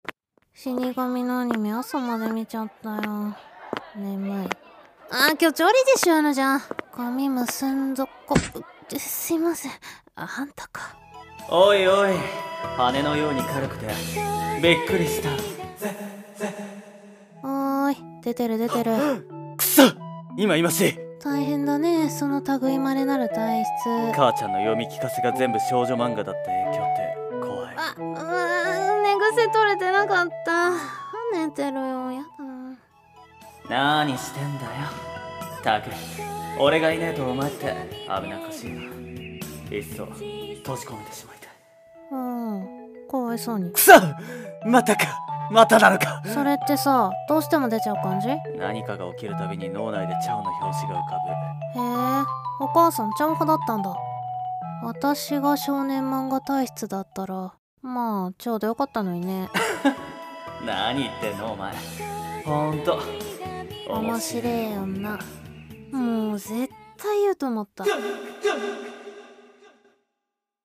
ラブコメ体質【二人声劇】